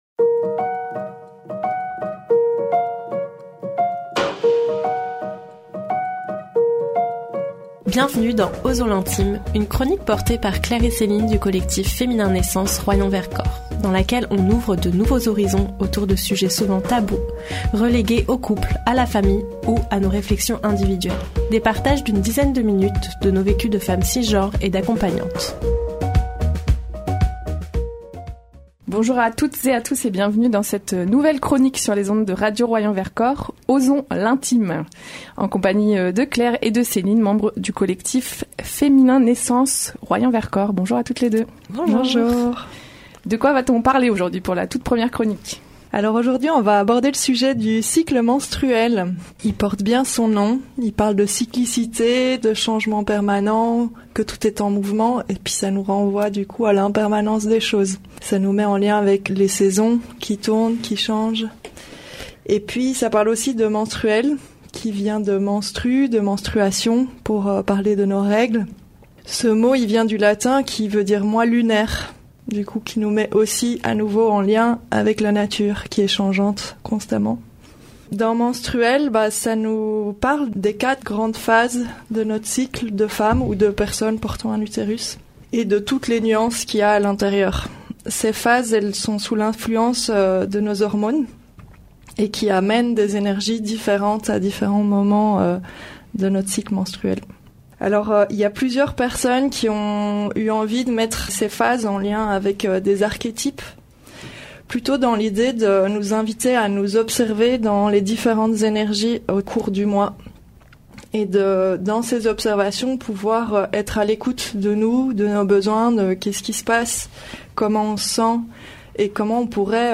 Une toute nouvelle chronique à découvrir sur les ondes de Radio Royans Vercors : Osons l’intime !